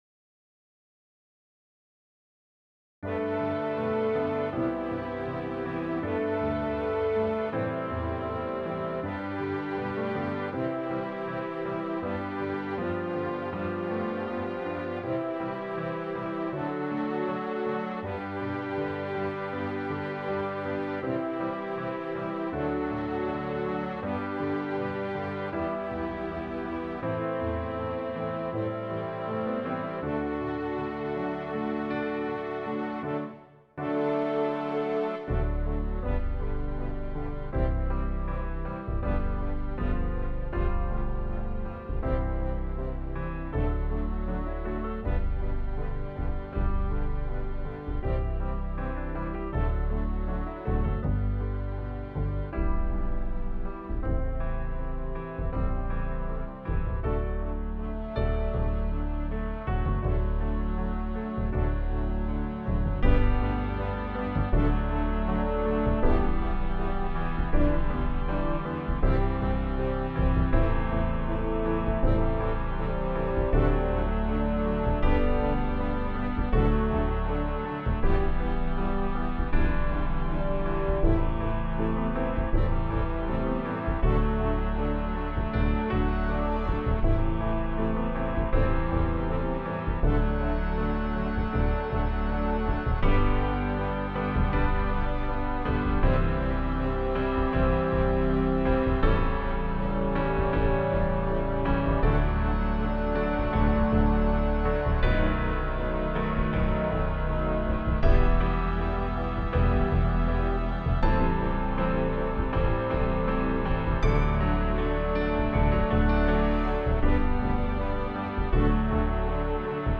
MP3 Recording (accompaniment)